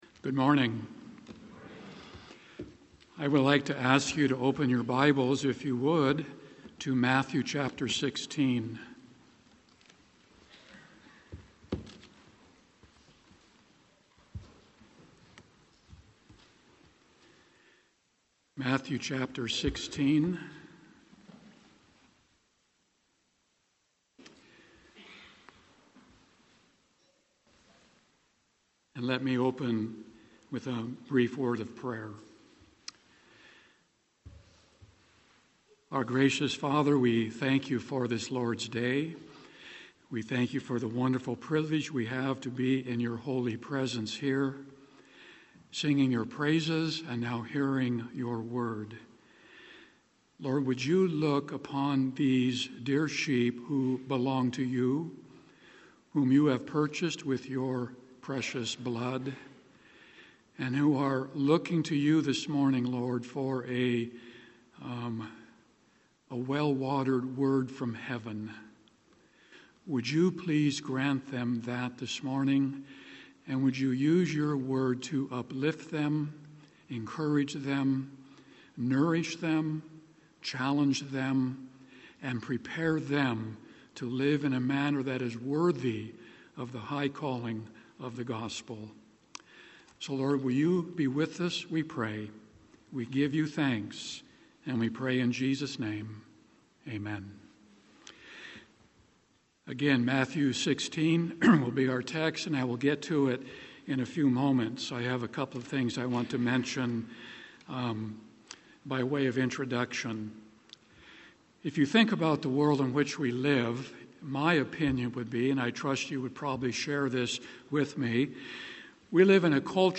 Sermons on Matthew 16:24-27 — Audio Sermons — Brick Lane Community Church